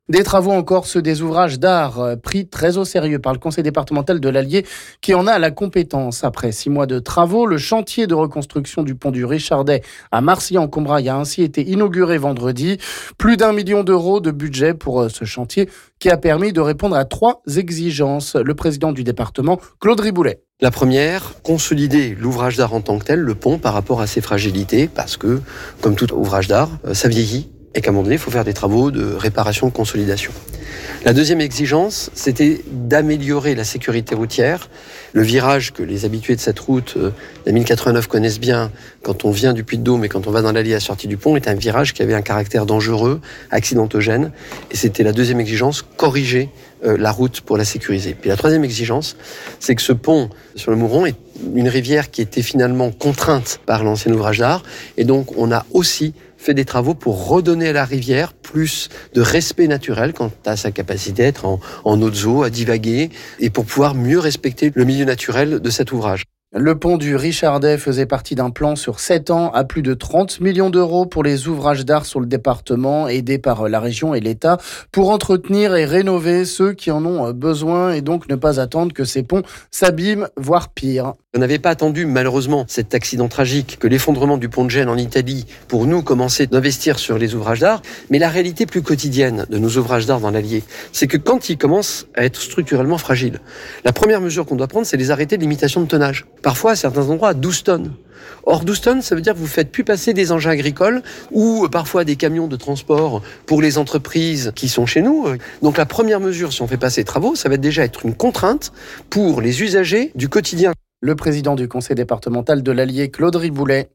Le président du conseil départemental de l'Allier Claude Riboulet nous en dit plus ici...